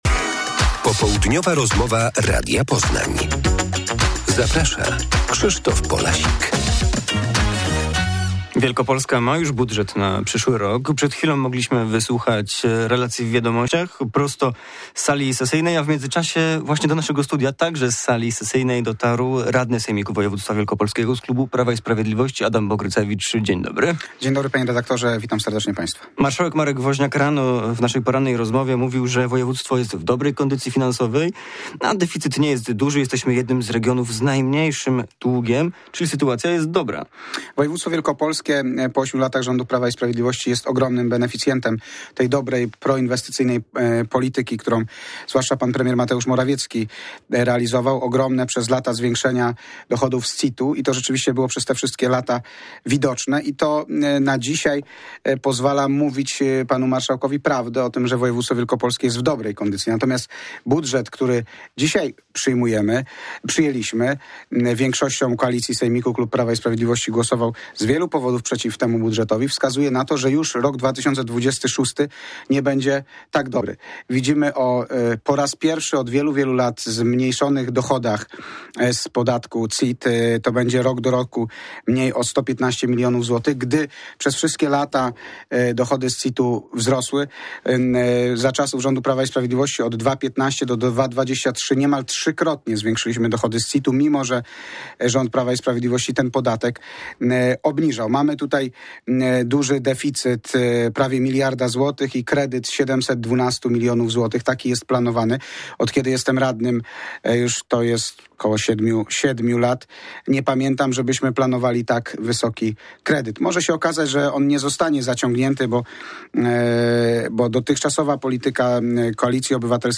Popołudniowa rozmowa Radia Poznań – Adam Bogrycewicz
Radny sejmiku województwa wielkopolskiego z klubu Prawa i Sprawiedliwości Adam Bogrycewicz mówi o głosowaniu nad budżetem regionu.